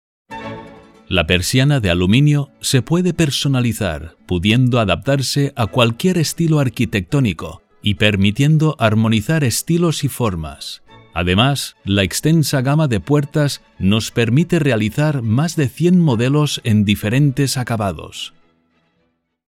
Experience in: Corporate Videos, E-learning, Documentaries, Training Videos, Webs, Audio Guides, TV, Radio, Games, Telephony, etc. Voice-overs done for: E-Bay, BBVA, RENFE, Iberdrola, Acciona, Vodafone, C.A.S.A. aeronáutica, Hyundai, Tele5 Atlas, SM Publicaciones, La Caixa, Mexicana Airlines, NH Hoteles, Volkswagen, Loctite, Rockwell, Mercadona, Caprabo, Consum, Gandía TV…etc Own recording studio. A Neumann U87 mic is used for all voice work.
Spanish (castilian) voice with over 20 years experience, can be a warm voice or authoratative.
Sprechprobe: Industrie (Muttersprache):